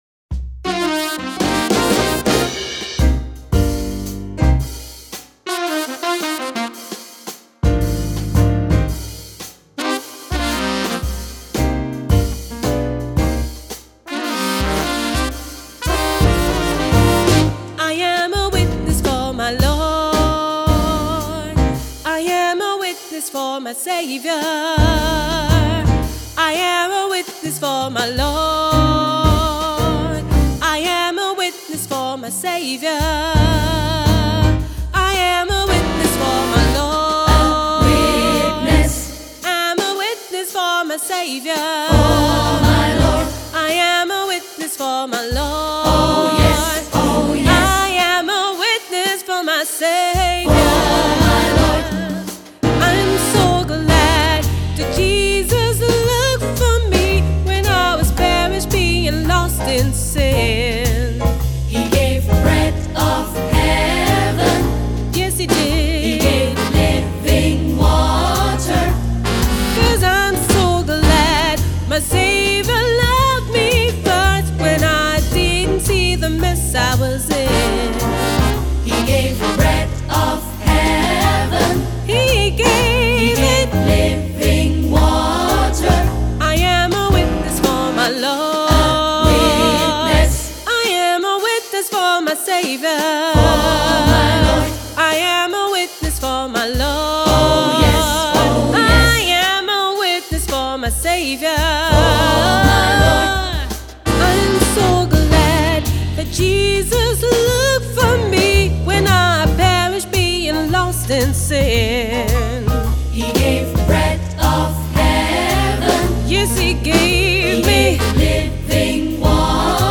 • SAB, auch SSA, opt. Solo + Piano